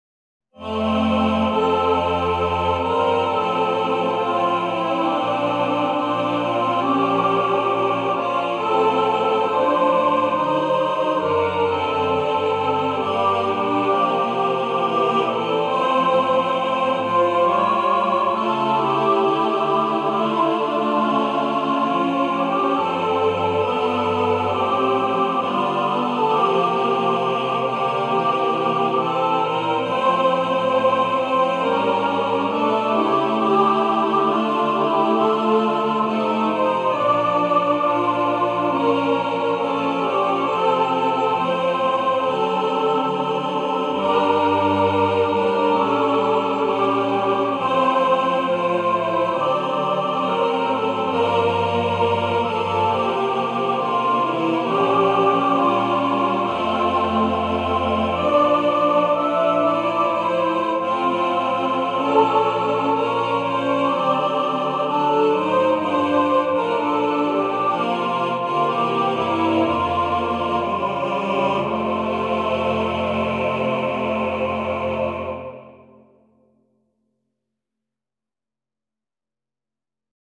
A beautiful, sacred hymn